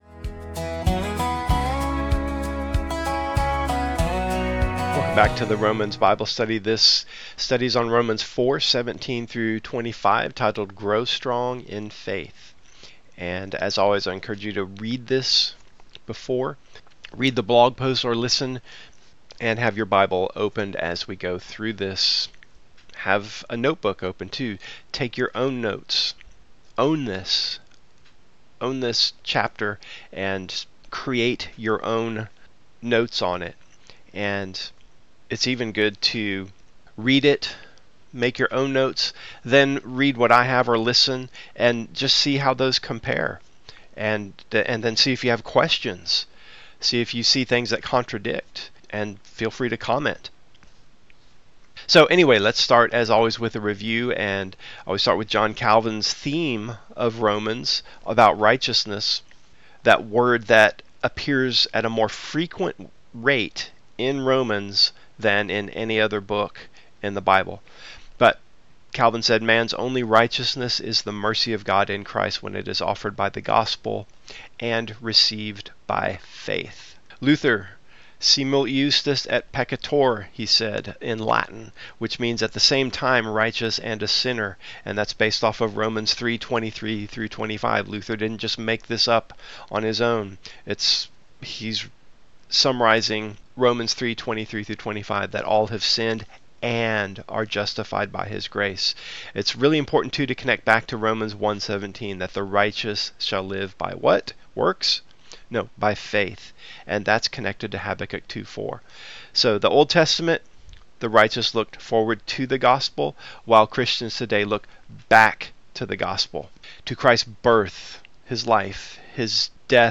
Listen to the study here: Romans 4:17-25